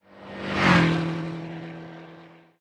highway / oldcar / car9.ogg
car9.ogg